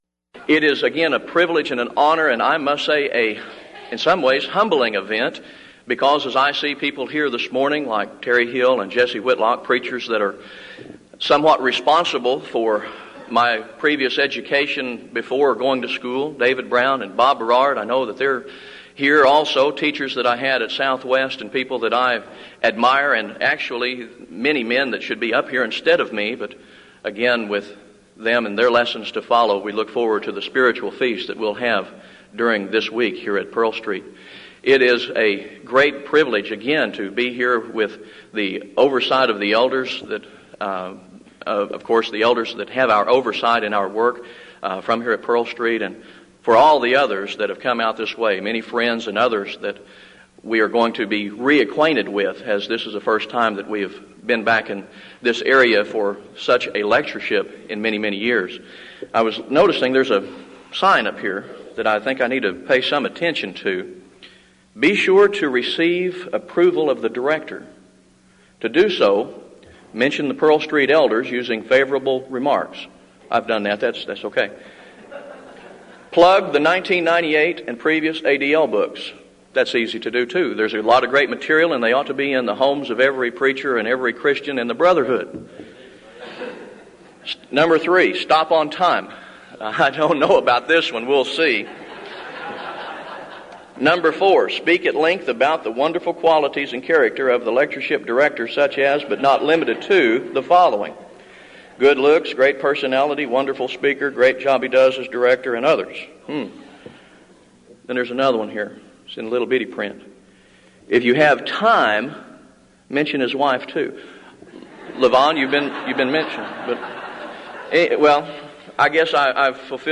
Event: 1998 Denton Lectures Theme/Title: Studies in the Books of I, II Peter and Jude